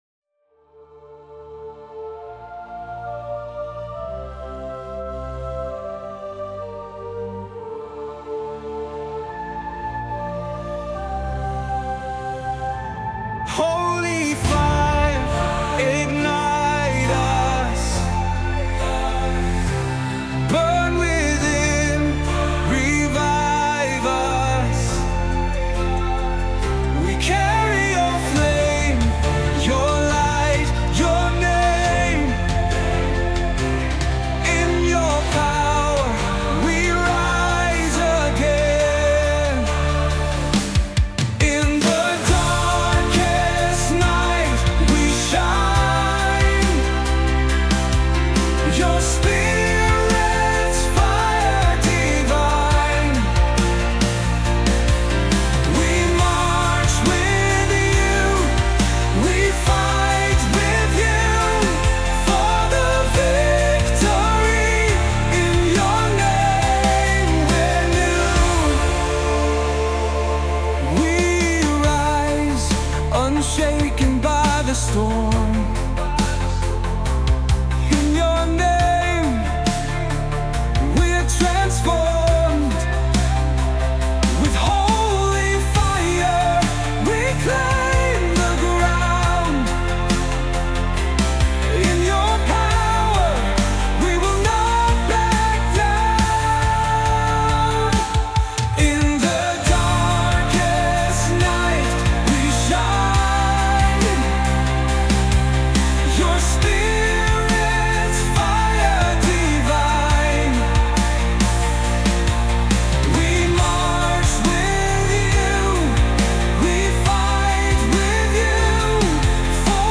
With bold lyrics and a powerful melody